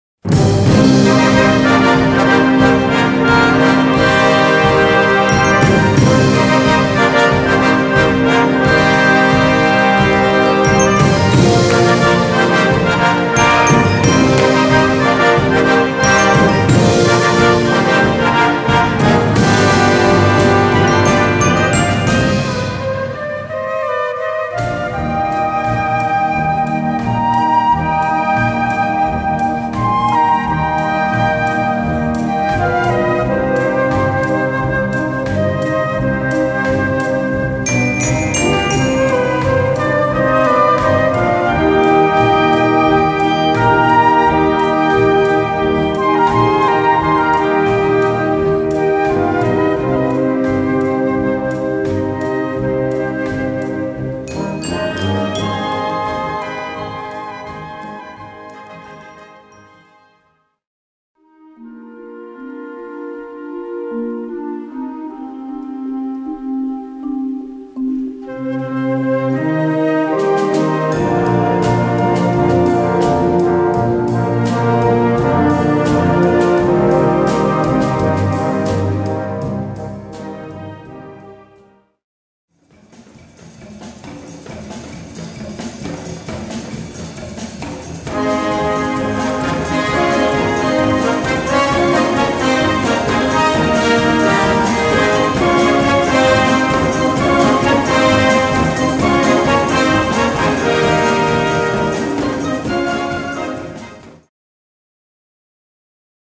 Gattung: Medley
Besetzung: Blasorchester